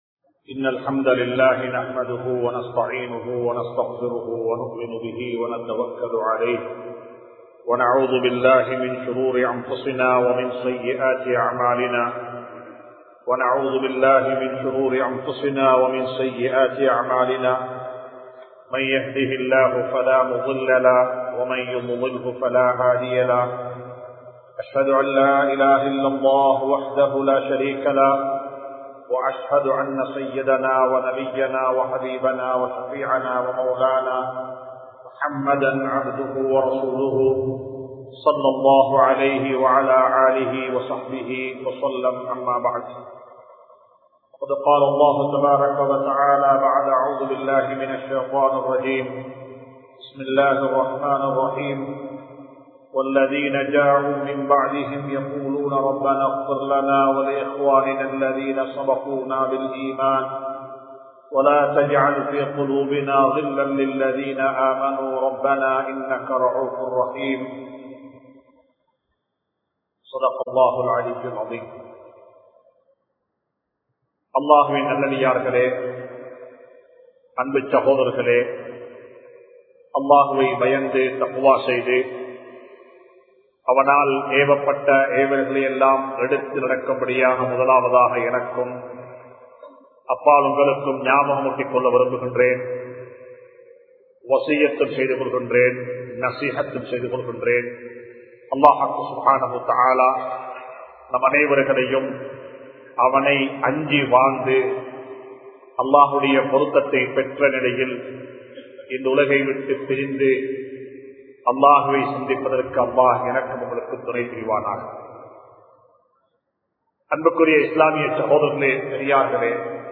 Uwais Al Qarni(Rah)Avarhalin Mun Maathiriyana Vaalkai (உவைஸ் அல் கர்னி(றஹ்)அவர்களின் முன்மாதிரியான வாழ்க்கை) | Audio Bayans | All Ceylon Muslim Youth Community | Addalaichenai
Majma Ul Khairah Jumua Masjith (Nimal Road)